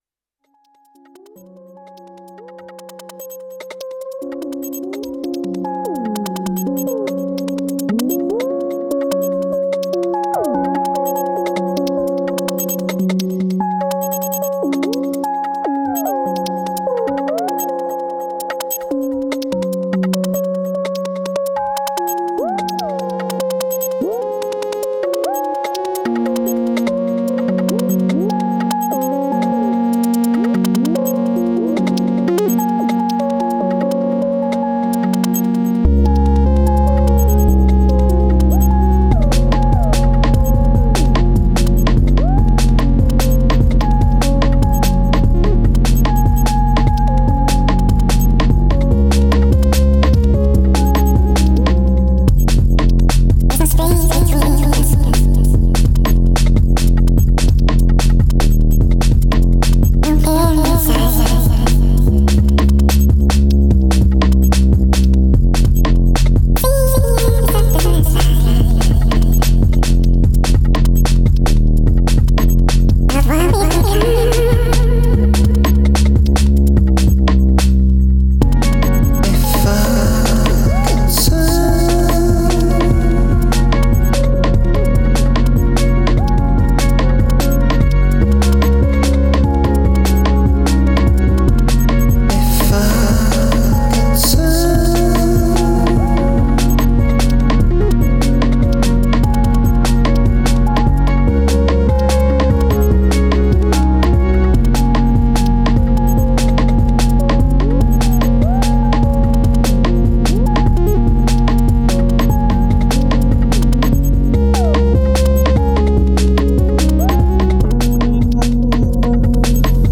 Here’s a sloppy arrangement of something that’s starting to resemble a song…
I’ve added some singing which was recorded from the OP-XY mic into a drum track and then sequenced by the XY. I also added a guitar part which was sampled from me just goofing around on my electric guitar running into my pedal board, it serves as some sort of outro.
Song mode keeps tripping me up because no matter how many passes I make the verse scene always starts with the vocals mid verse…